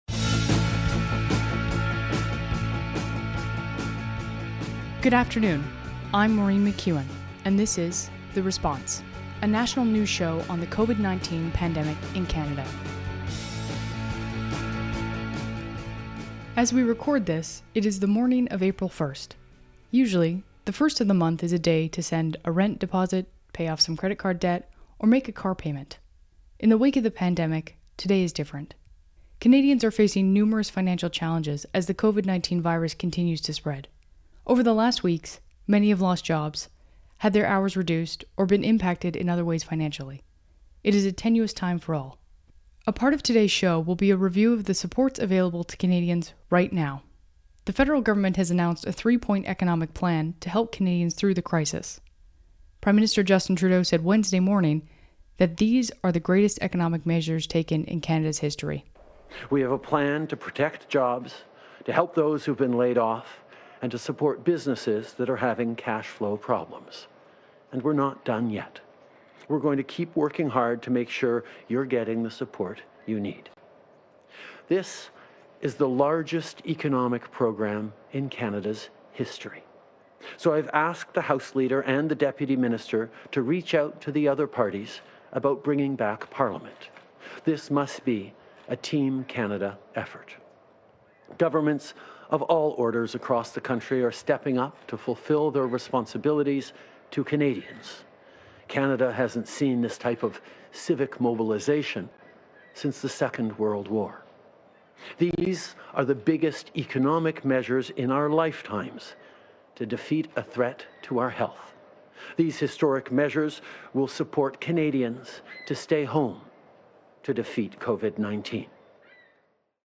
National News Show on COVID-19
Credits: Audio clips: Canadian Public Affairs Channel.
Type: News Reports